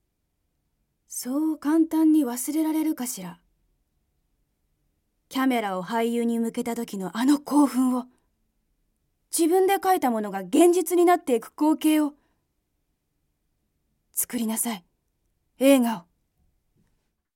セリフB
ボイスサンプル